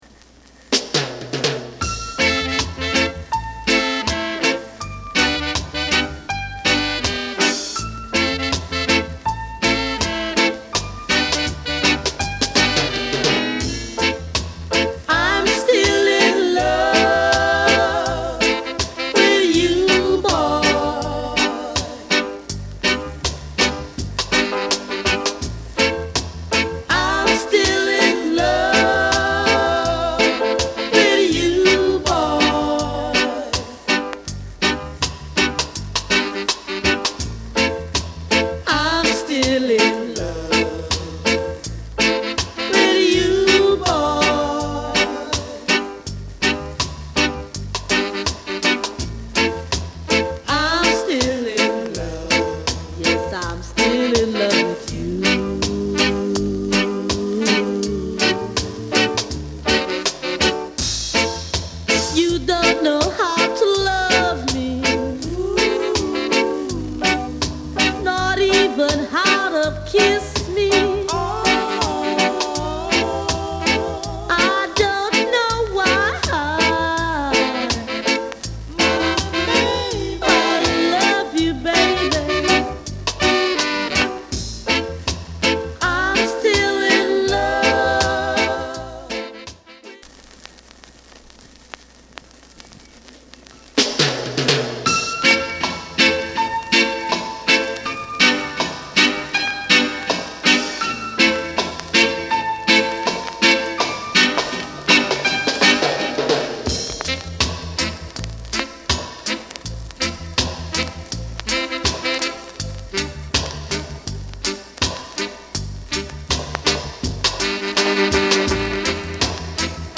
Reggae / Lovers Rock 可憐な歌声とタイトな演奏が弾ける、ラヴァーズ・ロック不滅の金字塔！
鉄壁のミリタント・ビートに乗る、瑞々しく甘い歌声が完璧な一曲です。